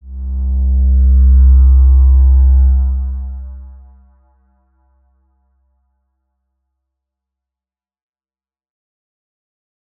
X_Windwistle-C1-pp.wav